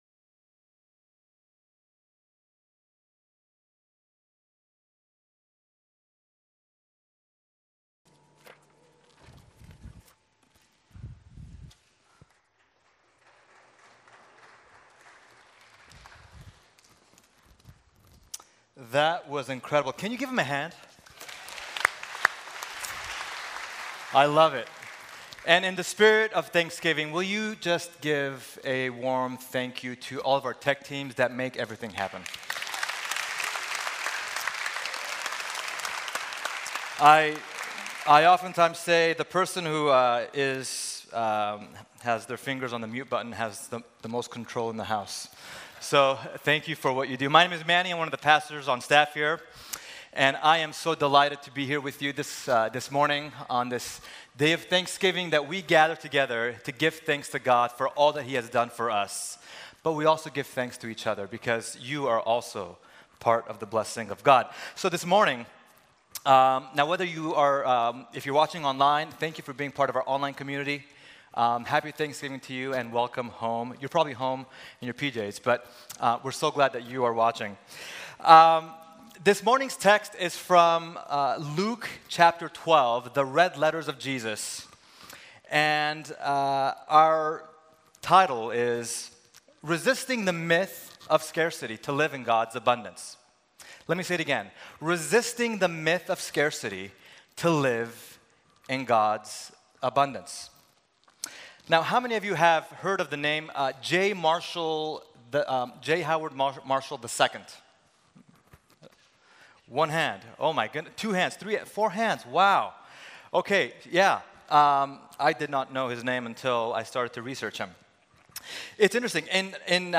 Thanksgiving Service Thanksgiving Sermon